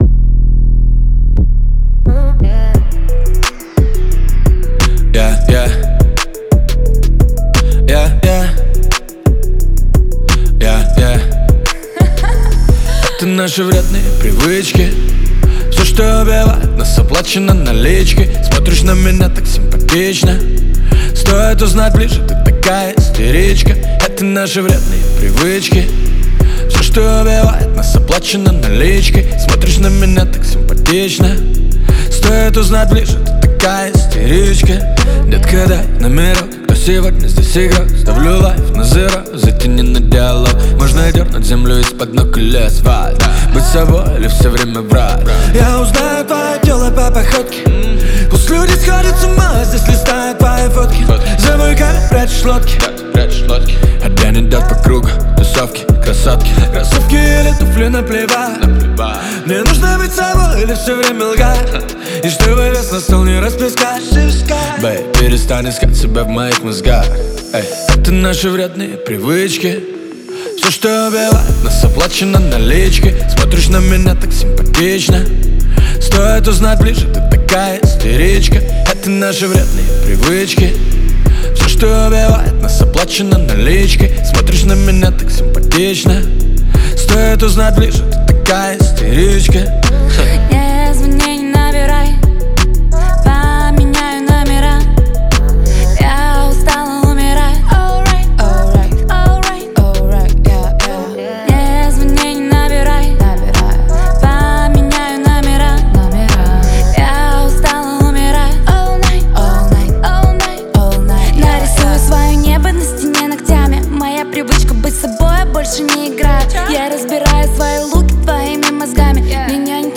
это зажигательная песня в жанре поп